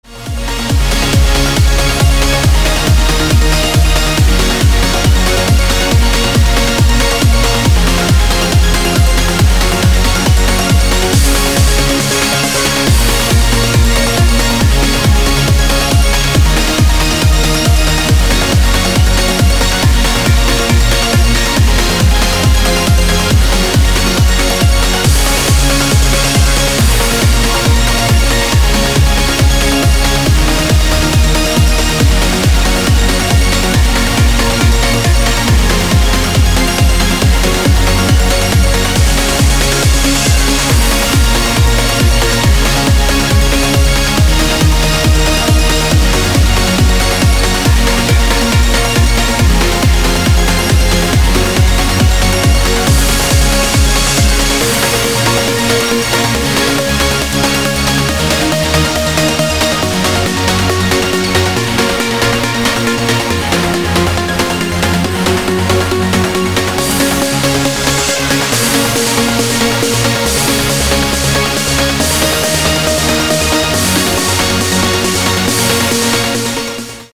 • Качество: 320, Stereo
dance
Electronic
club
Trance
electro